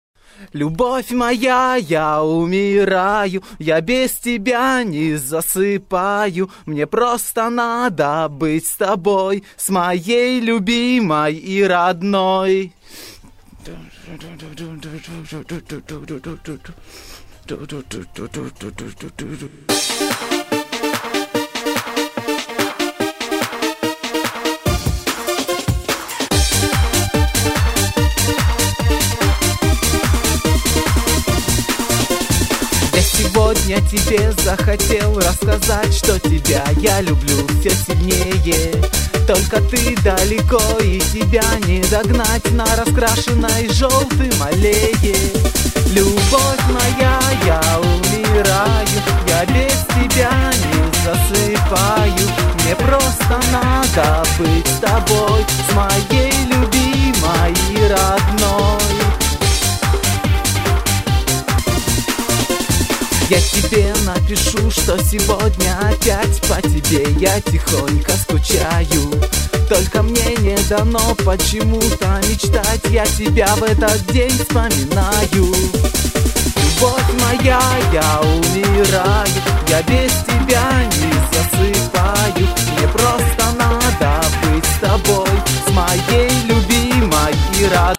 Очень прилично и сиграно легко.